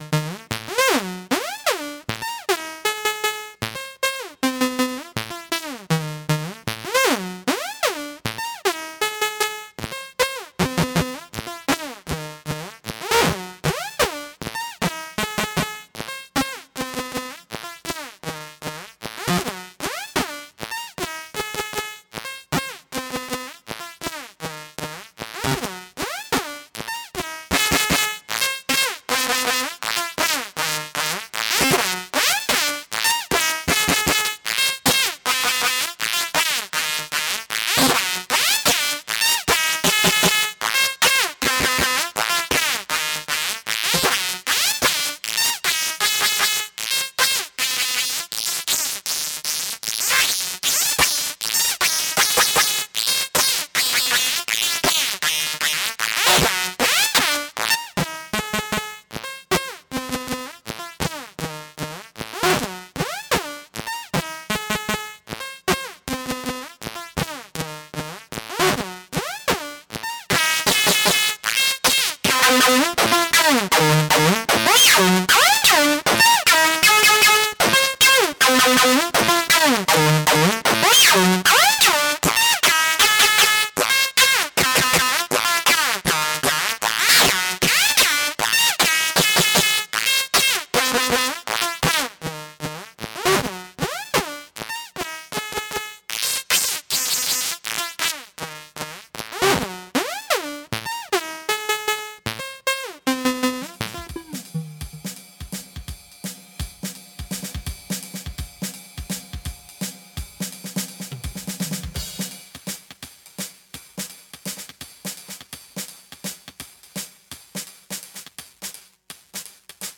Hi-Pass distortion is a digital "brick wall" hi-pass that can have distortion applied to get a raspy & sharp spikey sound.
BTW, mp3 does this great injustice, it sounds so much better live!
HiPassDist1.mp3